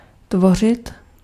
Ääntäminen
US : IPA : [ˈspɛɫ]